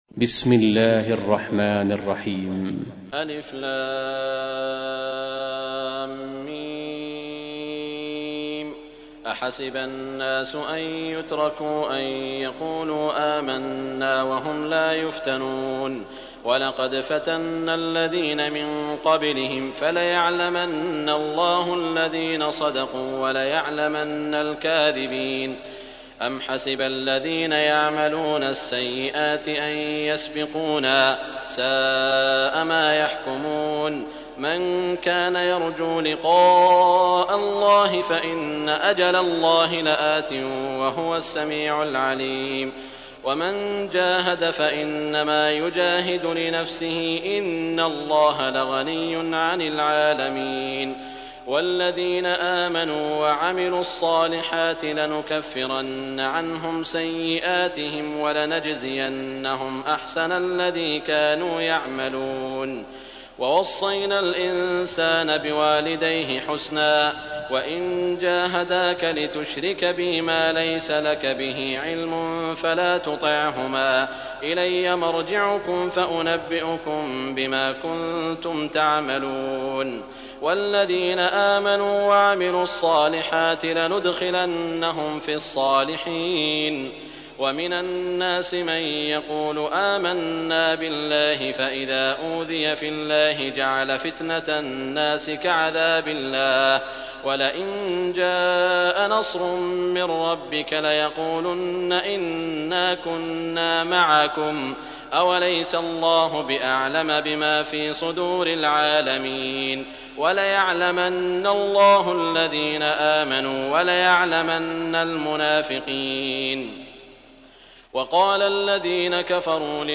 سورة العنكبوت | القارئ سعود الشريم